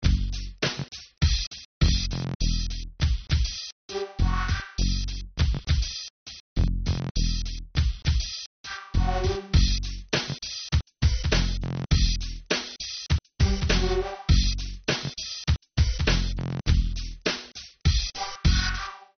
(инструментальный); темп (96); продолжительность (3:57)